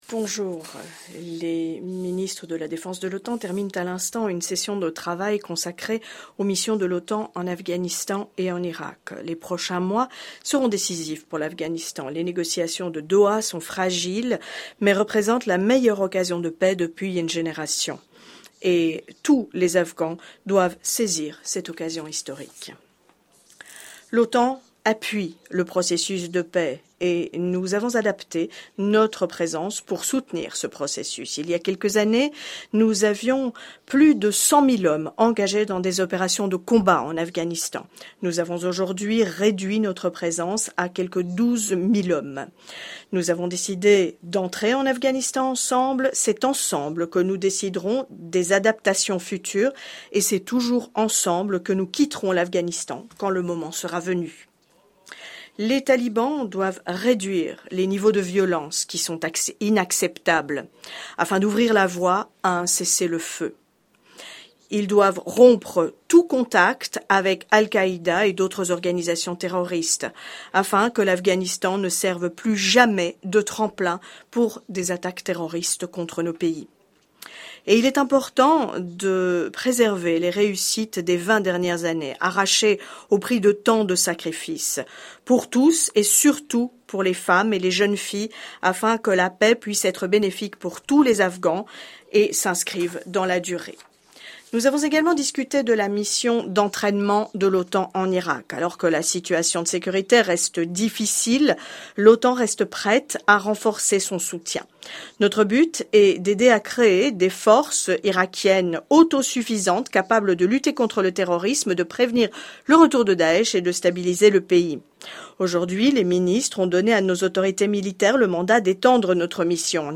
Online press conference
by NATO Secretary General Jens Stoltenberg following the second day of the meetings of NATO Defence Ministers